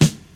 • Raw Steel Snare Drum Sample D# Key 99.wav
Royality free snare sample tuned to the D# note. Loudest frequency: 1258Hz
raw-steel-snare-drum-sample-d-sharp-key-99-oy6.wav